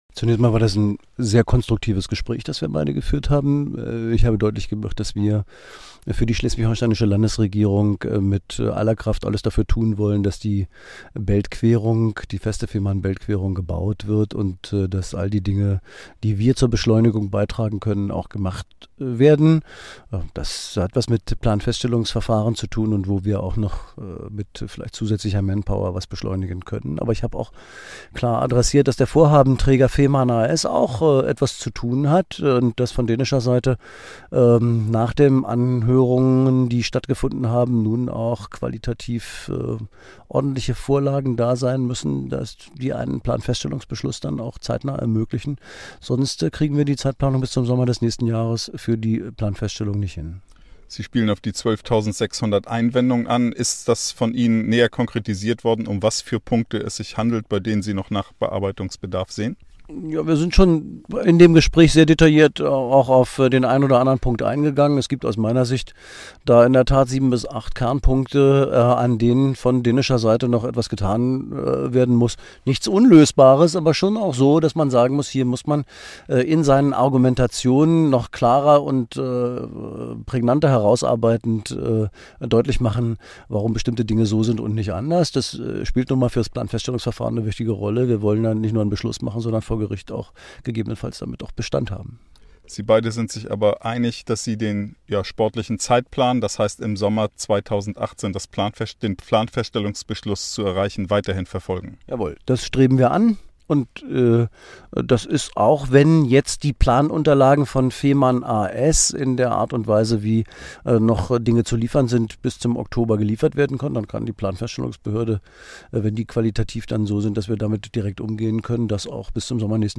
Buchholz_TonBuchholz sagte zu dem Treffen unter anderem